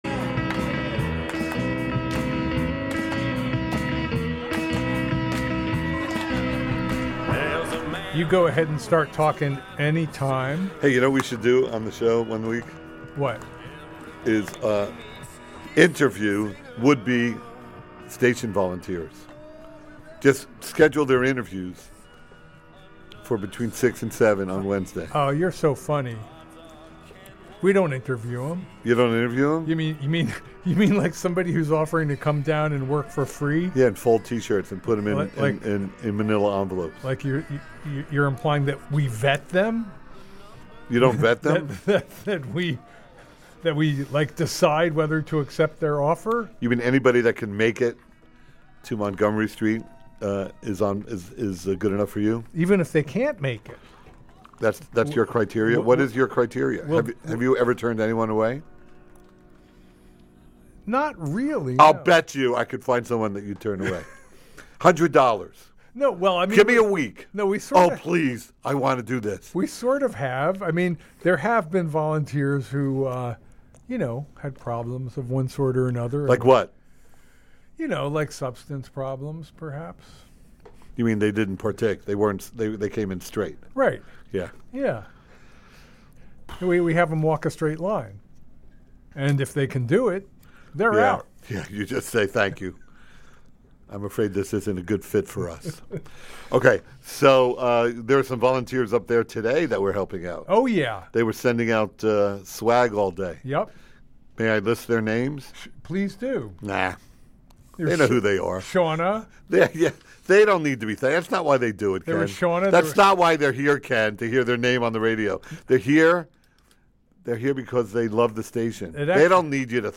Stunt radio which subjects the radio audience to concepts and topics which mature adults should not have to endure.